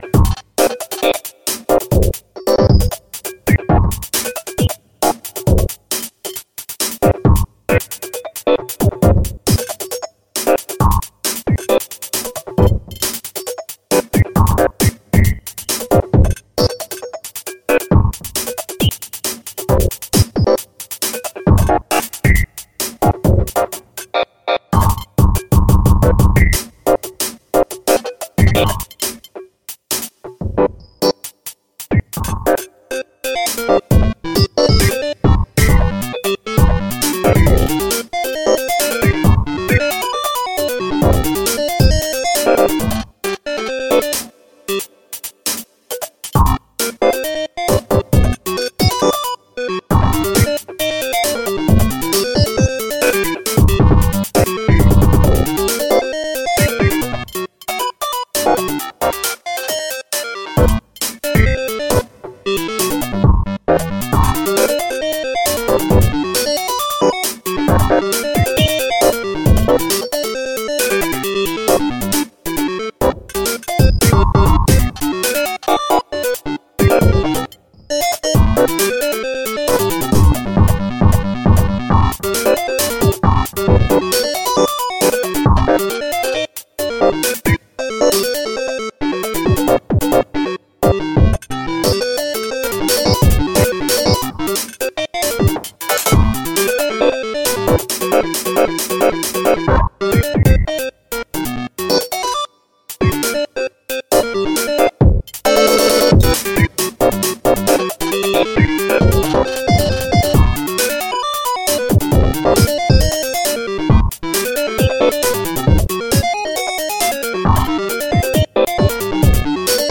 Another tidalcycles track here. Experimenting with layers of polyrhythms and some polymeters, plus some harmony which I rarely do in tidal.